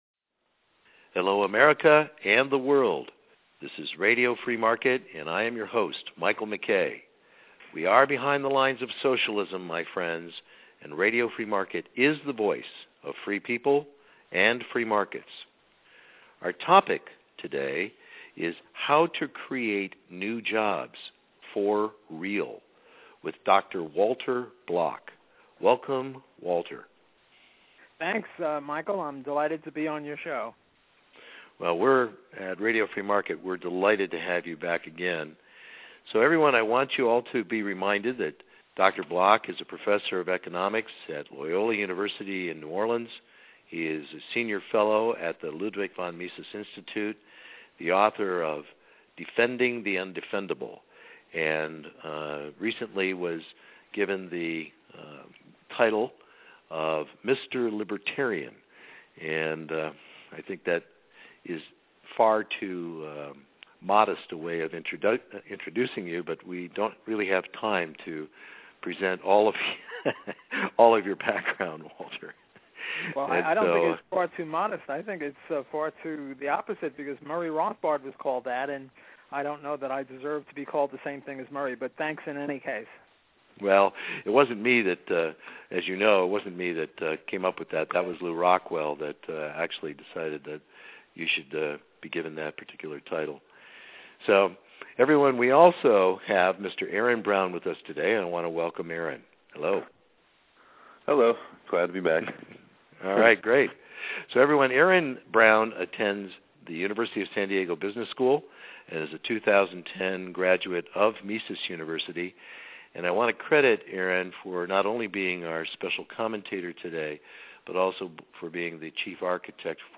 Dr. Walter Block on How To Create New Jobs – For Real Part I 02/19/11 RFM Subscribe ** How To Create New Jobs – For Real Part I , with Dr. Walter Block** Dr Block is a Professor of Economics at Loyola University in New Orleans and a Senior Fellow at the Ludwig von Mises Institute.